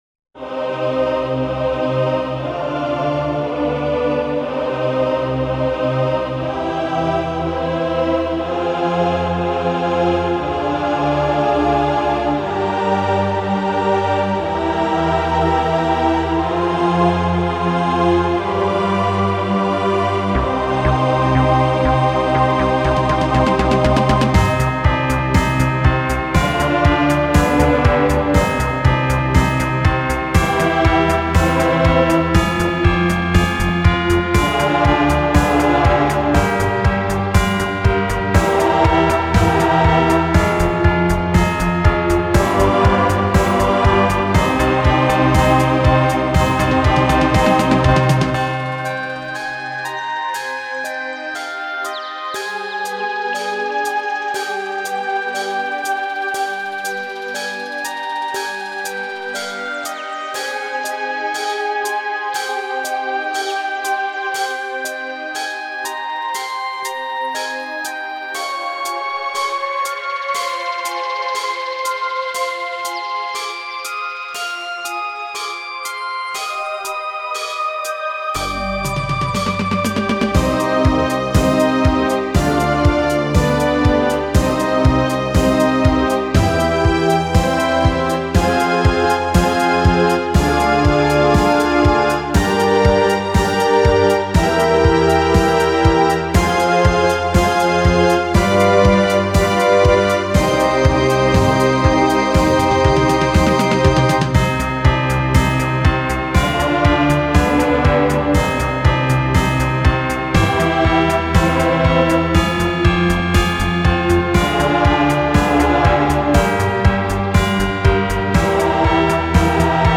Spacesynth Trance Techno
Neoclassical Healing Music
Ambient